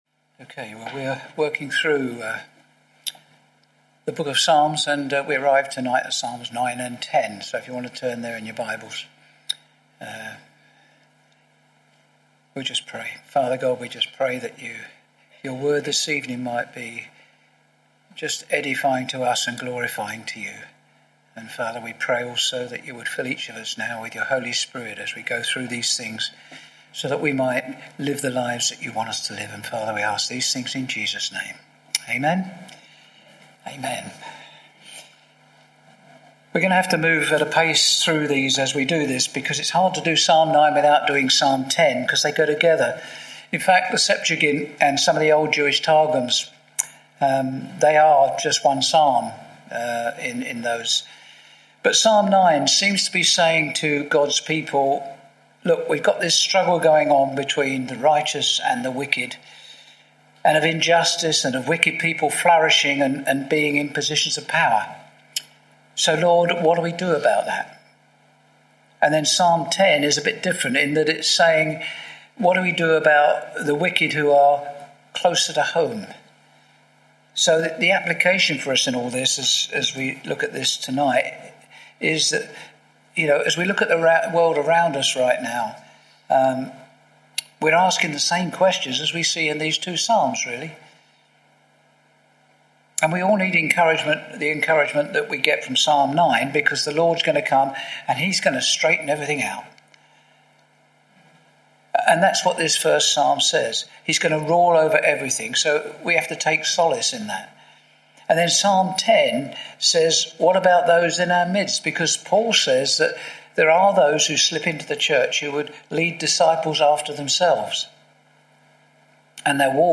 Share this: Share on X (Opens in new window) X Share on Facebook (Opens in new window) Facebook Share on WhatsApp (Opens in new window) WhatsApp Series: Sunday evening studies Tagged with Verse by verse